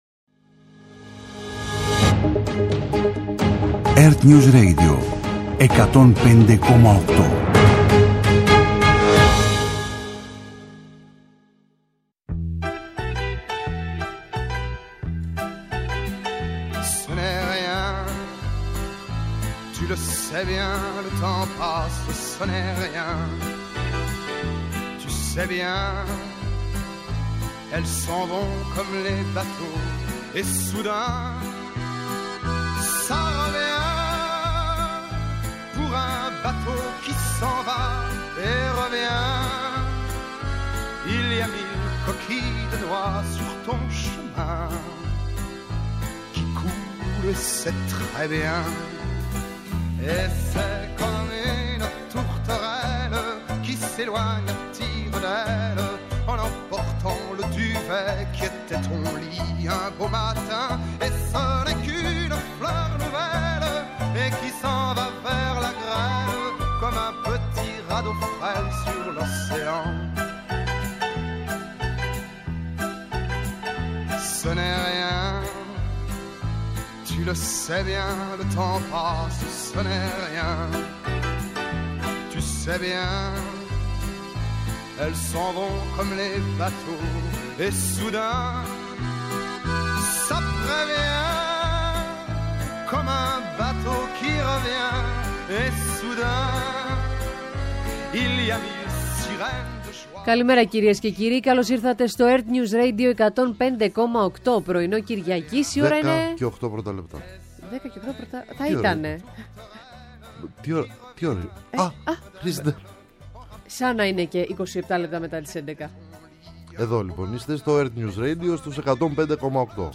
Ενημέρωση με έγκυρες πληροφορίες για όλα τα θέματα που απασχολούν τους πολίτες. Συζήτηση με τους πρωταγωνιστές των γεγονότων. Ανάλυση των εξελίξεων στην Ελλάδα και σε όλο τον πλανήτη και αποκωδικοποίηση της σημασίας τους.